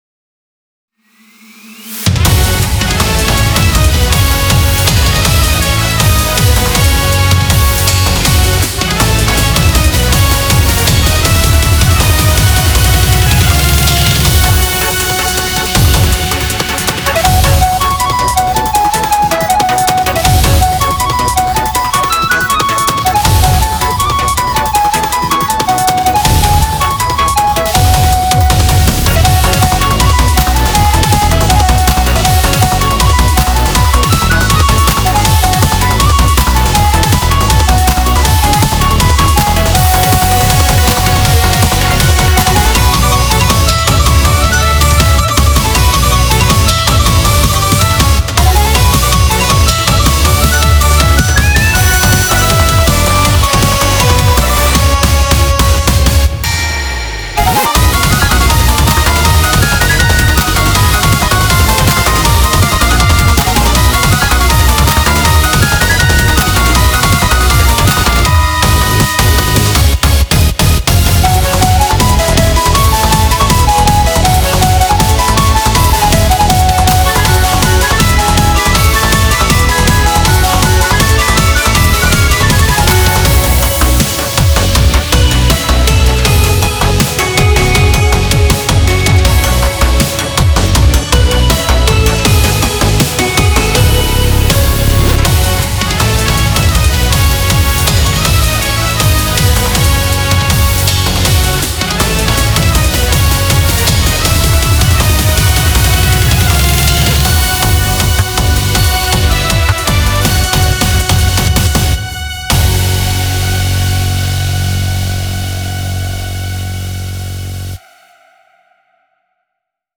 BPM165
Audio QualityCut From Video